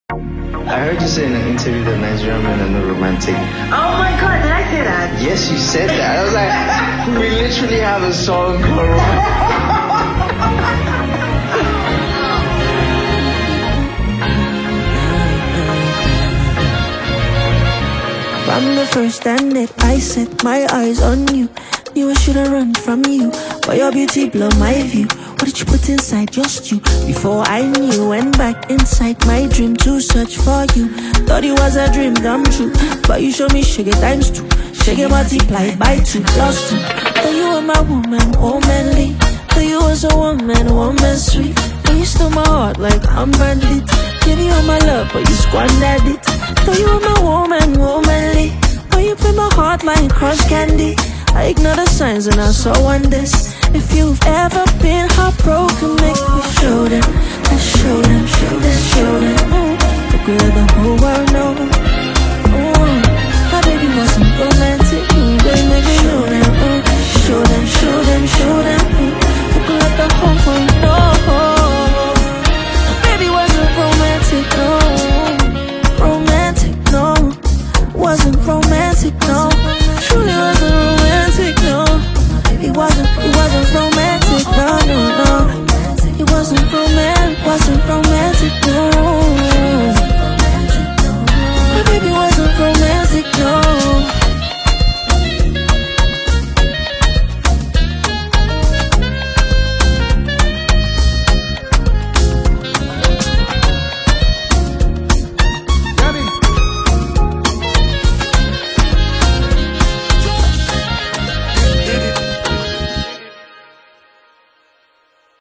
lighthearted Afropop record
smooth vocals and a catchy melody